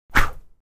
sword-gesture2
Tags: sword